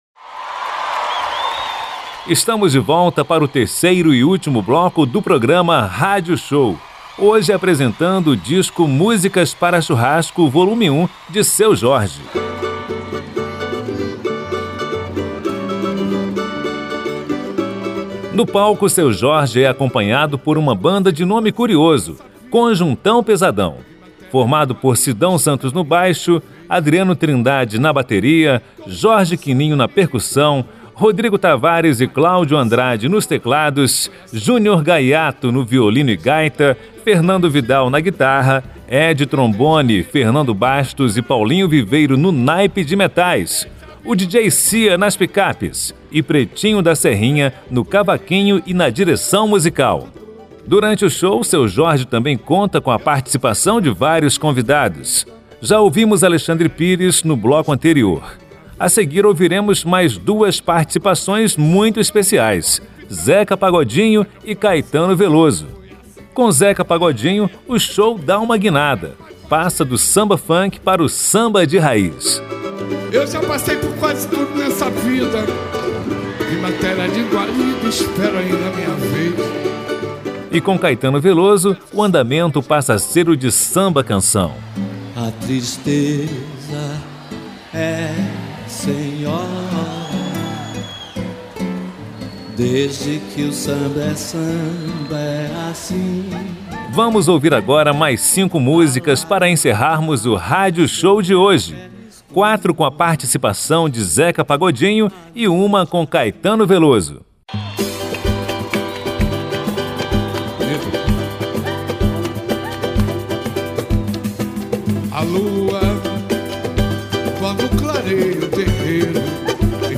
baixo
bateria
percussão
teclados
guitarra
violino e gaita
naipe de metais
pickups